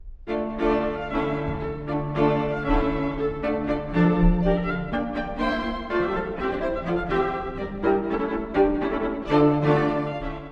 ↑古い録音のため聴きづらいかもしれません！（以下同様）
Scherzo ~Allegro non tanto e con fuoco~
民族感たっぷりのスケルツォです。
“Con fuoco”の名のとおり、この曲で一番活動的な楽章です。